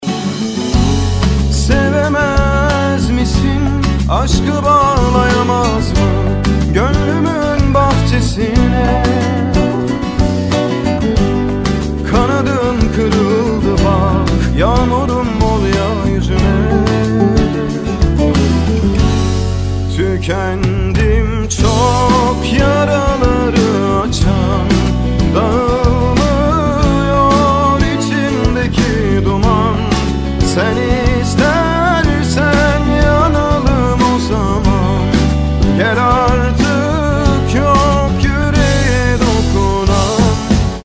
زنگ خواننده ترکی